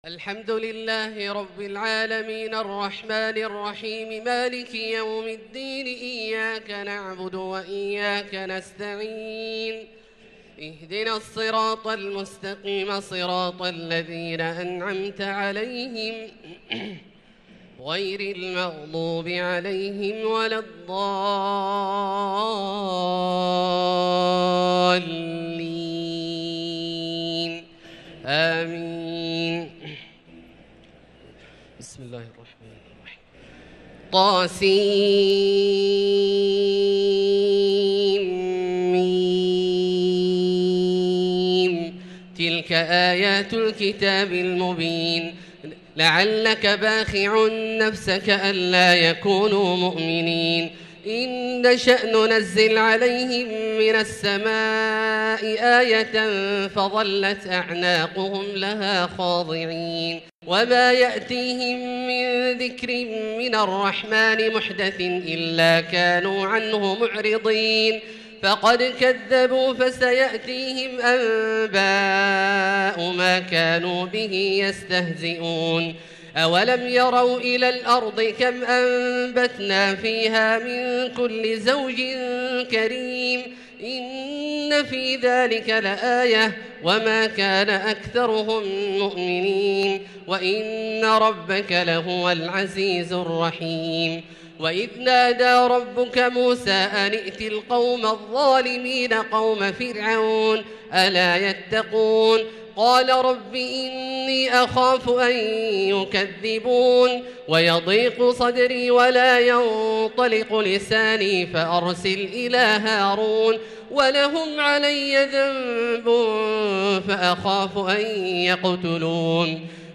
صلاة التراويح ليلة 23 رمضان 1443 للقارئ عبدالله الجهني - الثلاث التسليمات الأولى صلاة التراويح